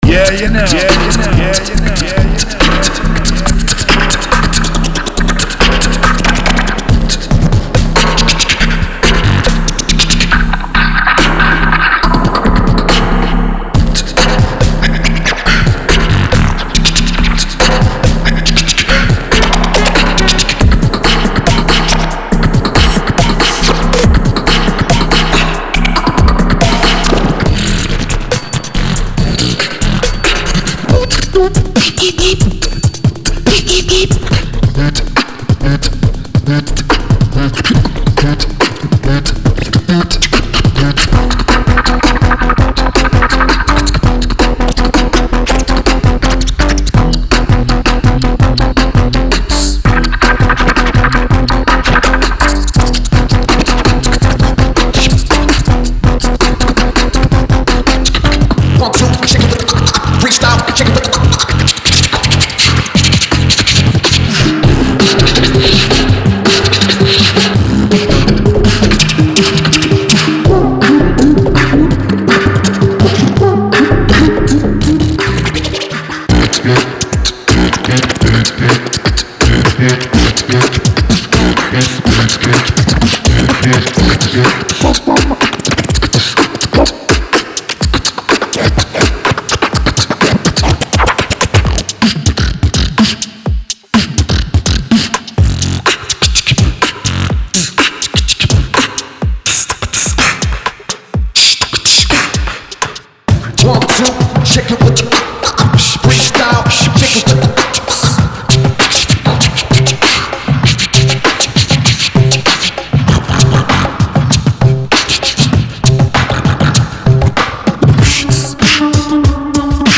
Genre:Hip Hop
テンポは85bpm〜174bpmに対応しており、ほぼすべてのセッションに簡単に組み込むことが可能です。
デモサウンドはコチラ↓